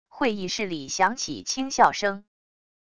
会议室里响起轻笑声wav音频